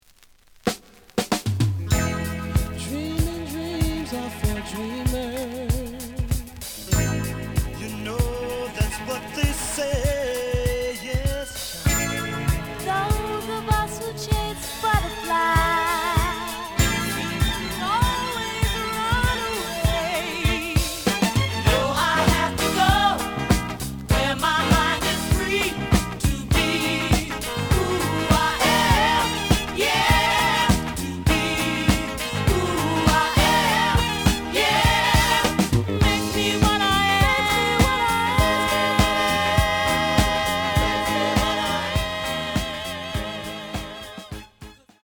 The audio sample is recorded from the actual item.
●Format: 7 inch
●Genre: Disco
Slight edge warp.